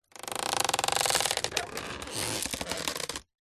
Скрипучий звук крышки гроба при открытии или закрытии Вариант 1